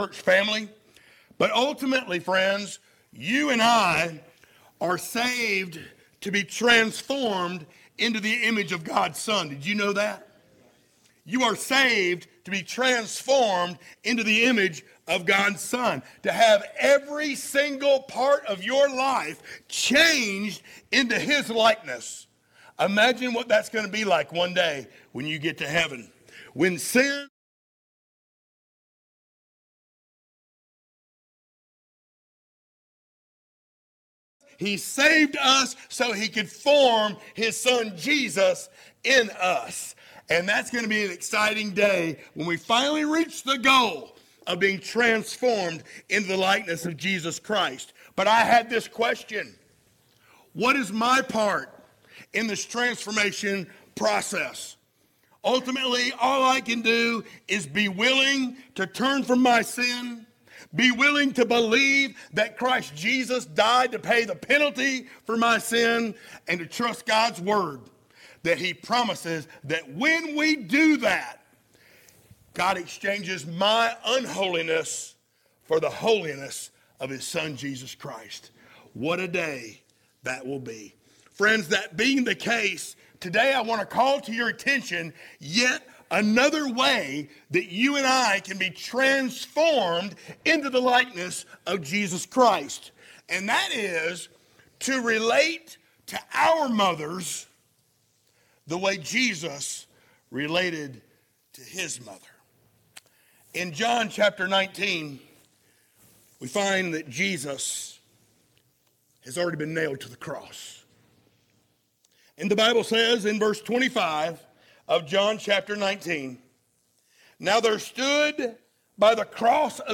sermons Passage: John 19:25-27 Service Type: Sunday Morning Download Files Notes Topics